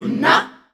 Index of /90_sSampleCDs/Voices_Of_Africa/ShortChantsShots&FX